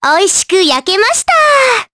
Cleo-Vox_Victory_jp.wav